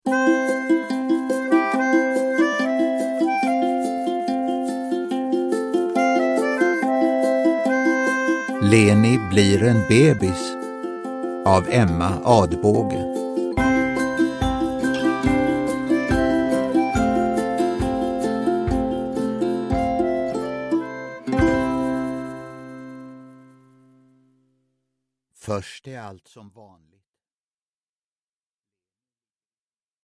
Leni blir en bebis (ljudbok) av Emma Adbåge | Bokon